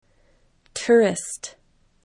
tourist   tʊrəst